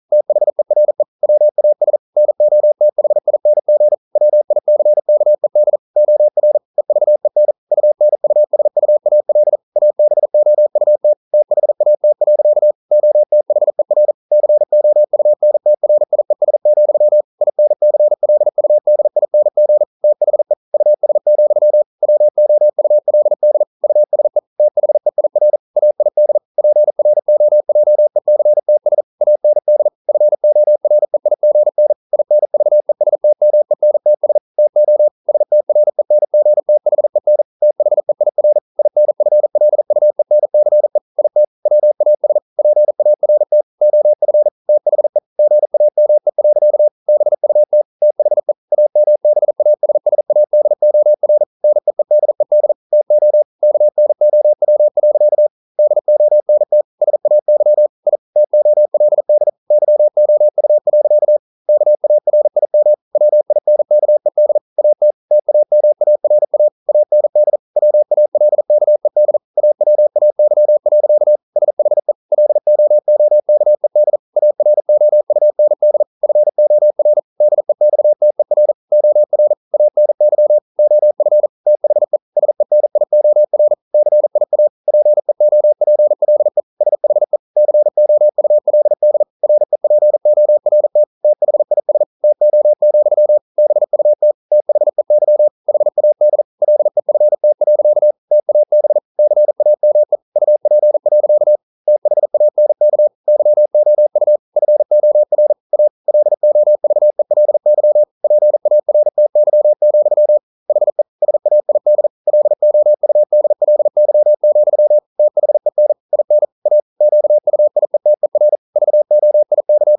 Never 41wpm | CW med Gnister